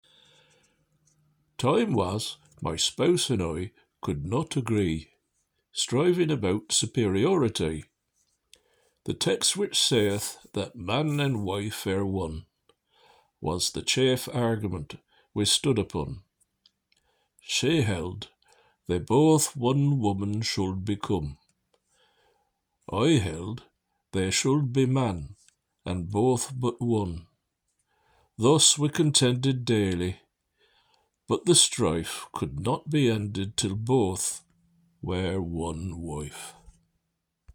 Readings from Franklin’s Poor Richard’s Almanack and his Reformed Mode of Spelling